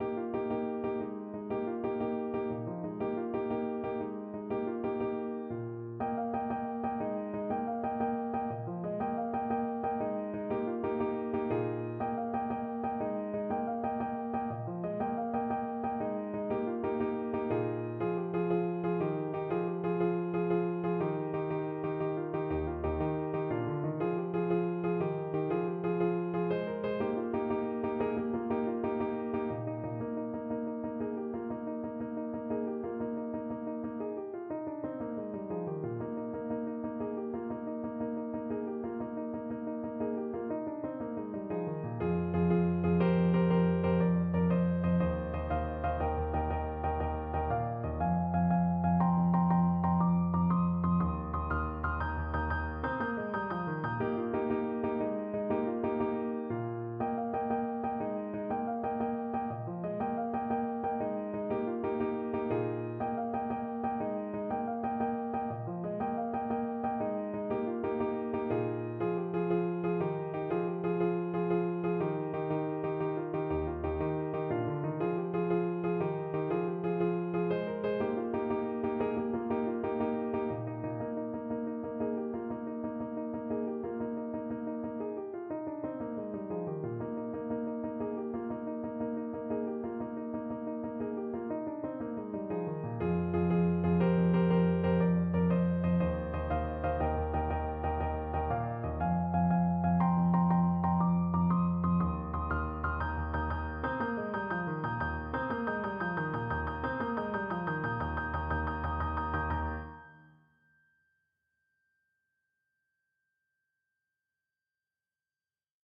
Clarinet
Eb major (Sounding Pitch) F major (Clarinet in Bb) (View more Eb major Music for Clarinet )
9/8 (View more 9/8 Music)
Traditional (View more Traditional Clarinet Music)